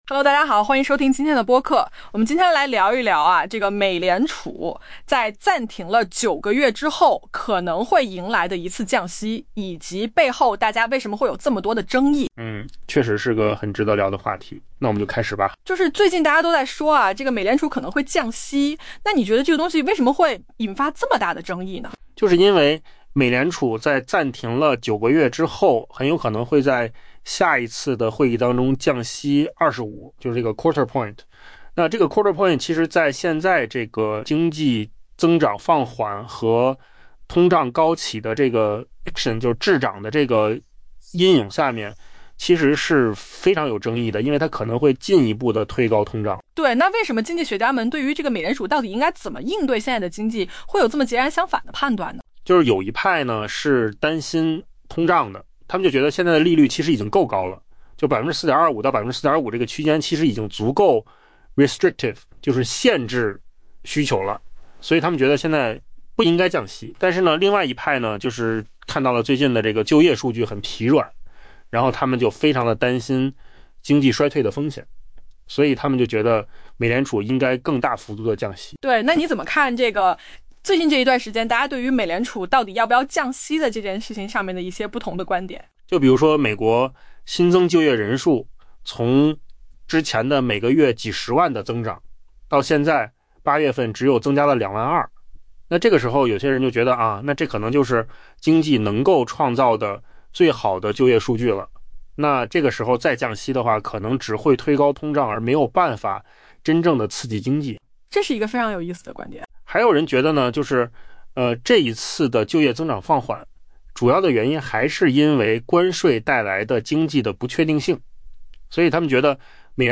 AI 播客：换个方式听新闻 下载 mp3 音频由扣子空间生成 在暂停降息九个月后，美联储几乎确定将在即将召开的会议上降息 25 个基点。